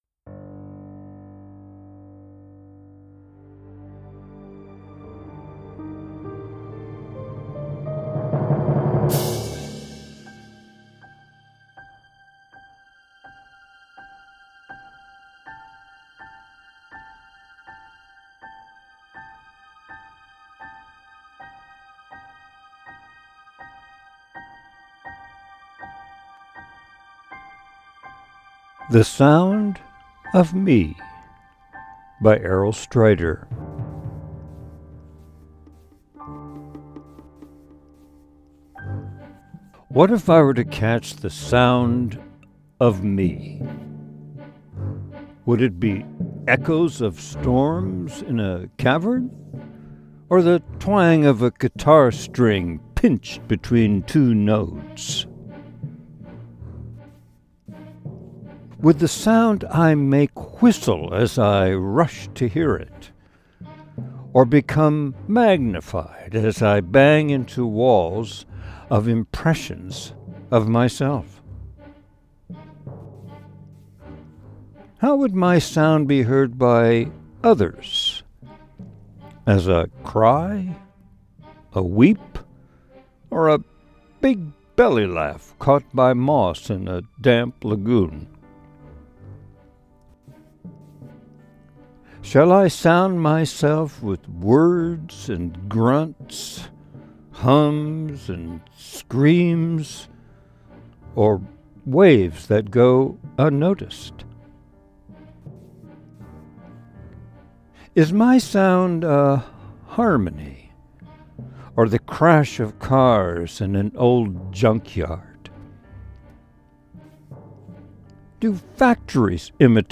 Sound-of-Me-w-Connectedness-Music.mp3